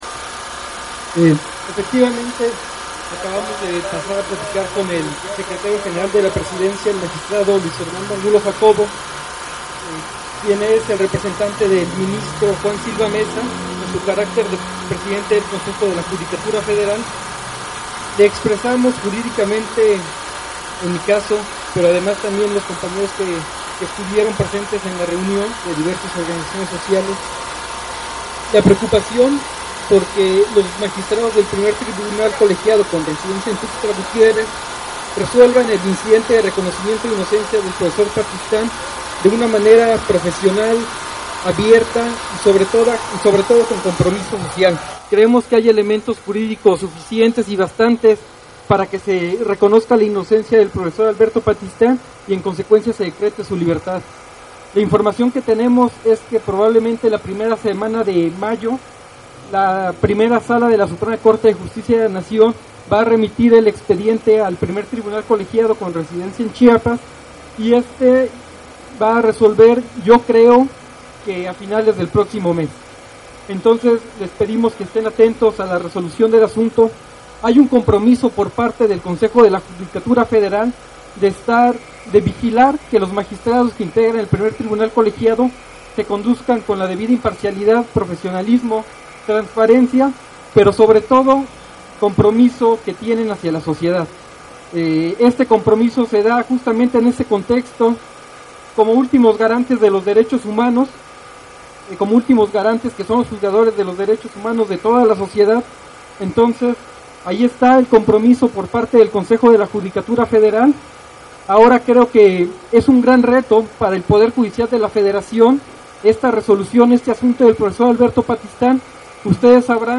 Desde muy temprana hora se congregaron aproximadamente 300 personas entre ellas compañerxs de la Sexta, organizaciones como la Coordinadora Nacional Plan de Ayala, medios libres, colectivos libertarios, entre otros.